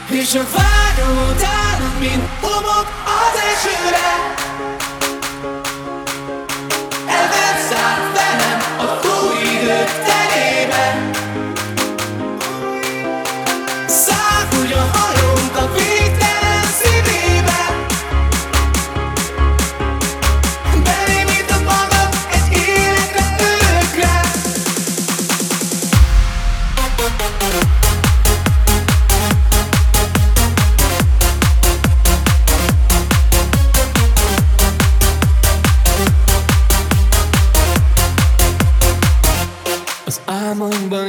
Hands Up Extended Version